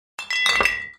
BottlePickup.ogg